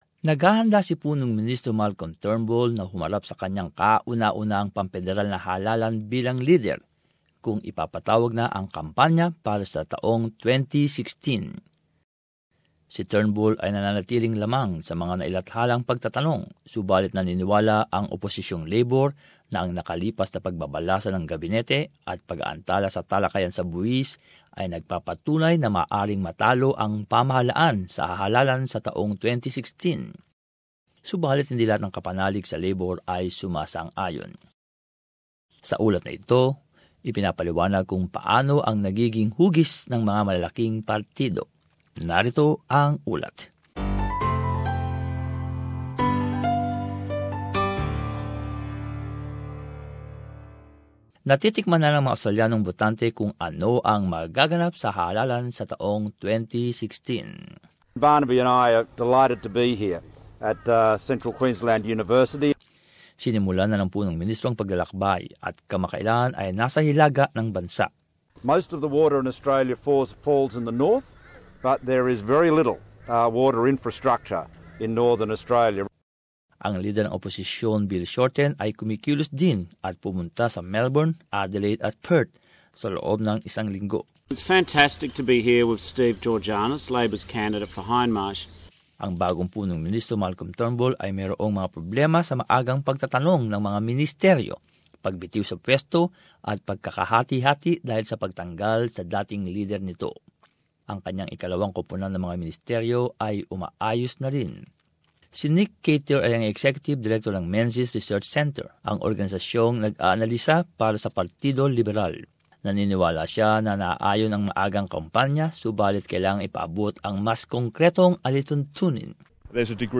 This background report explains how the major parties are looking.